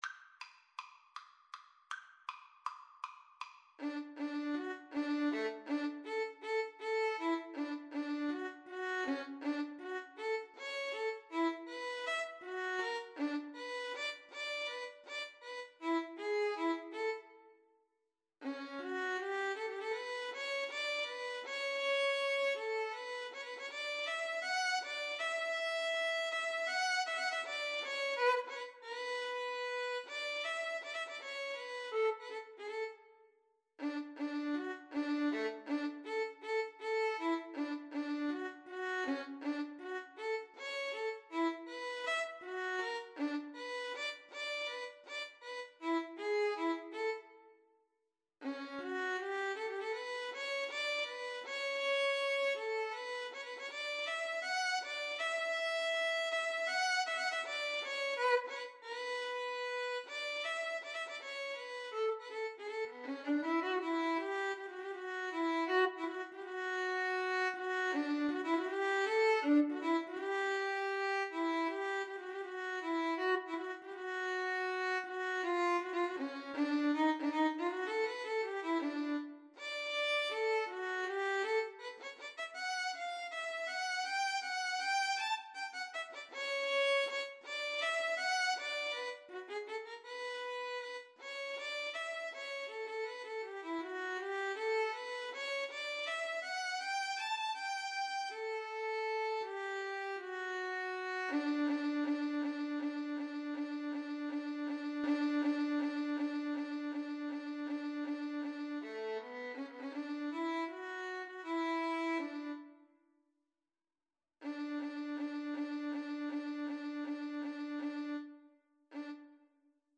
Free Sheet music for Violin Duet
Violin 1Violin 2
D major (Sounding Pitch) (View more D major Music for Violin Duet )
5/4 (View more 5/4 Music)
Allegro con grazia (=144) =160 (View more music marked Allegro)
Violin Duet  (View more Intermediate Violin Duet Music)
Classical (View more Classical Violin Duet Music)